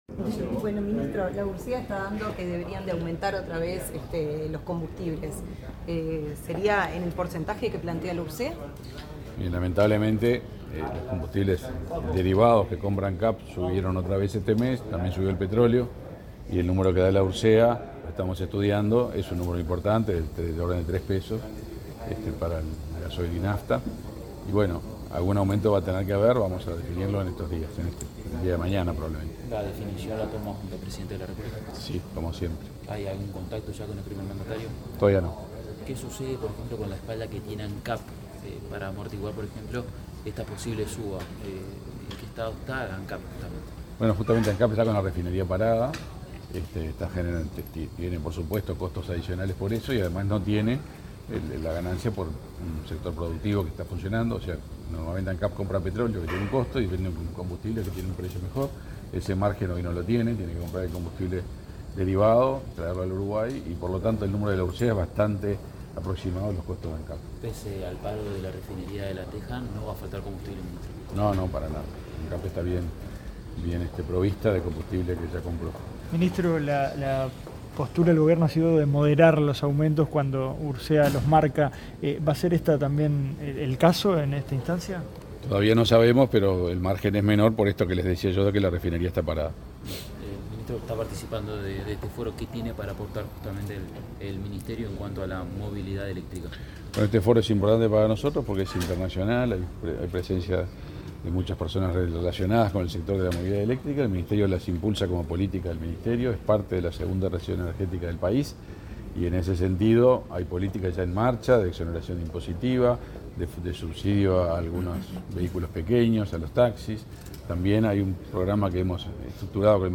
Declaraciones del ministro de Industria, Omar Paganini
Este jueves 28 en Montevideo, el ministro de Industria, Energía y Minería, Omar Paganini, fue entrevistado por medios informativos, antes de